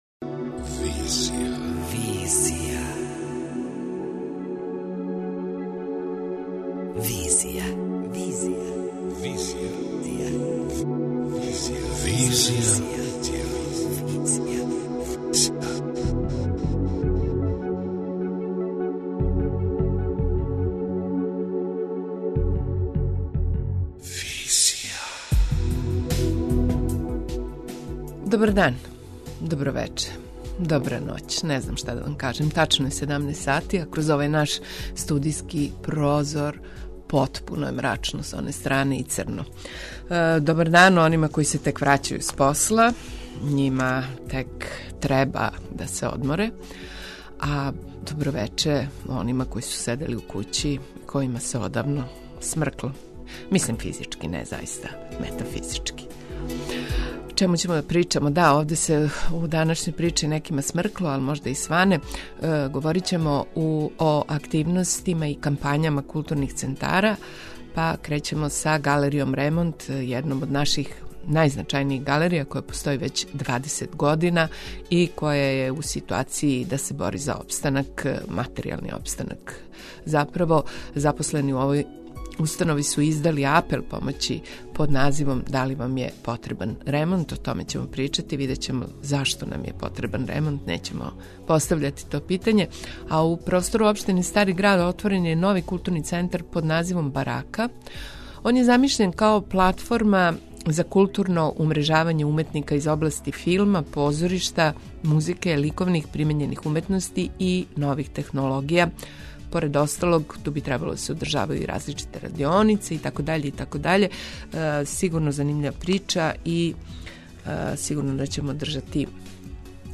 преузми : 27.74 MB Визија Autor: Београд 202 Социо-културолошки магазин, који прати савремене друштвене феномене.